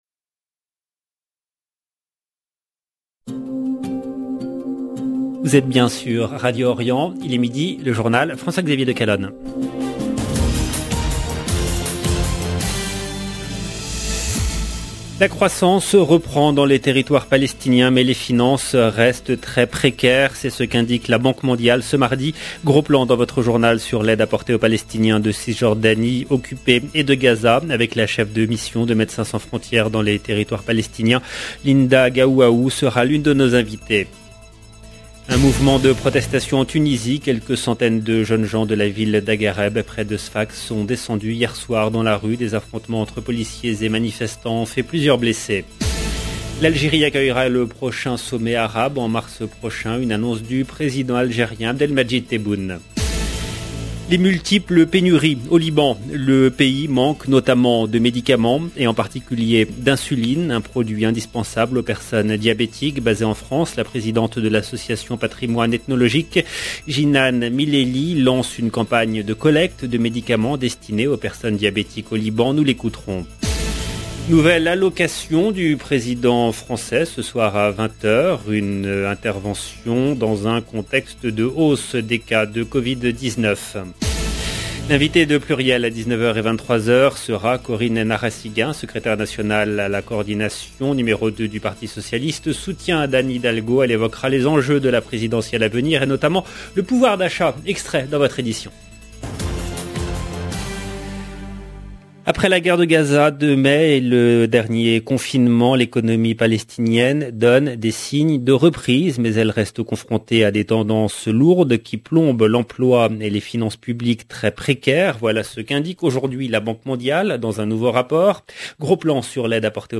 EDITION DU JOURNAL DE 12 H EN LANGUE FRANCAISE DU 9/11/2021